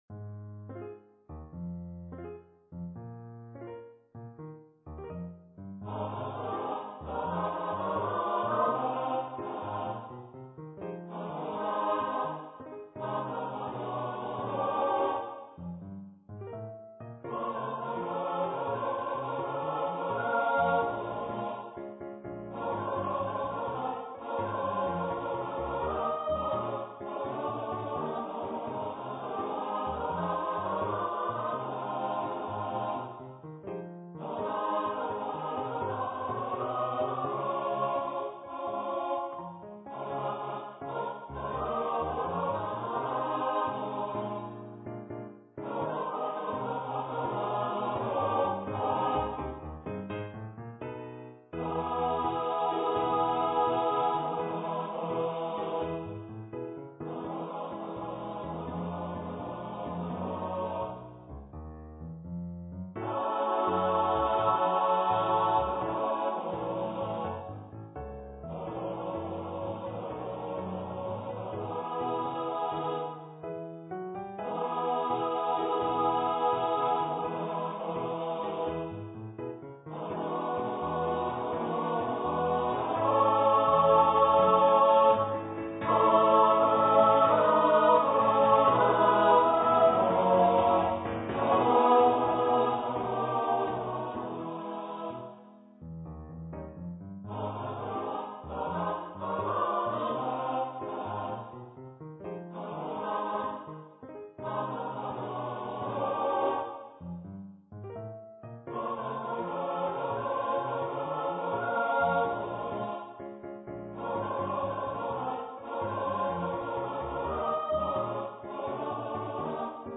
for SATB choir and Piano
An original light-hearted song of celebration.